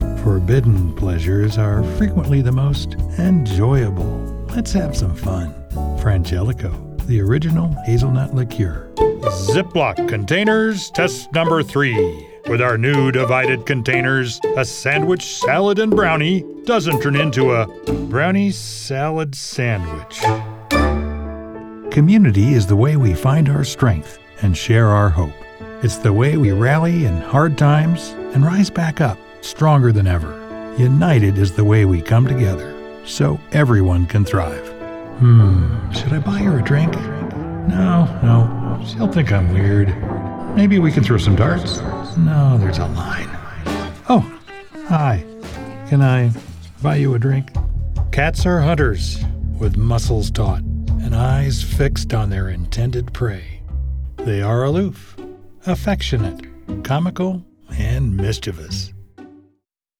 Voiceover Sample